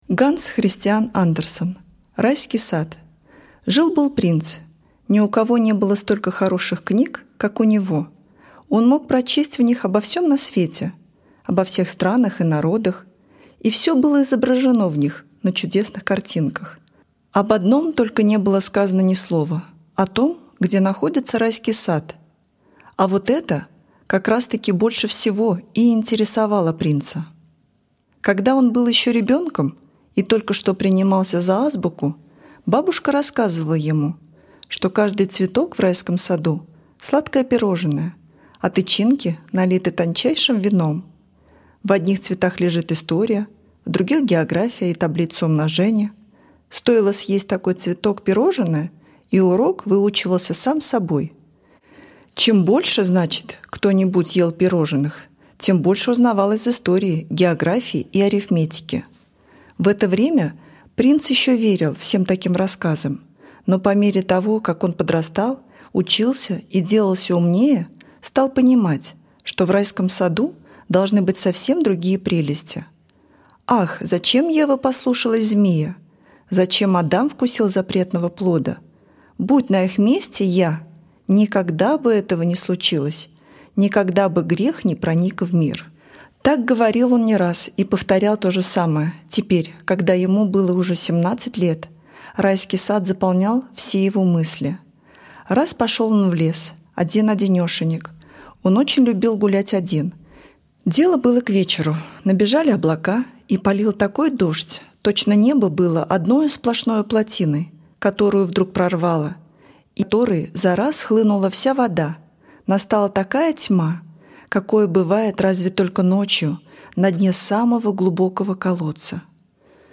Aудиокнига Райский сад